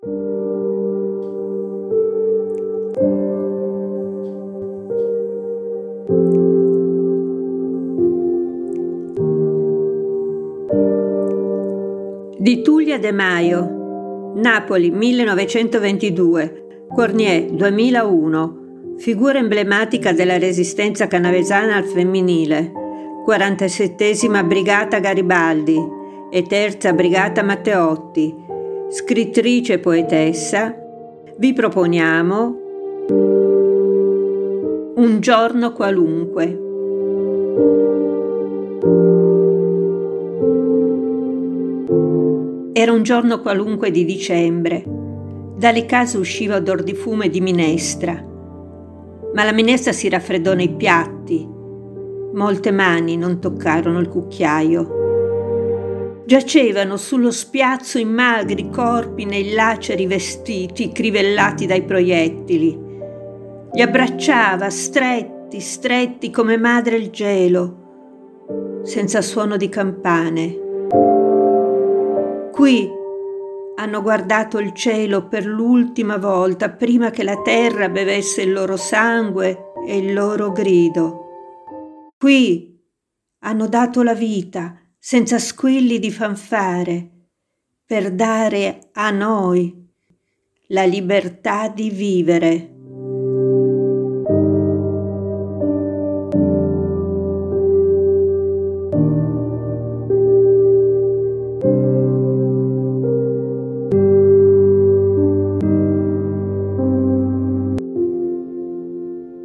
Tragic sad mourning grief despair lament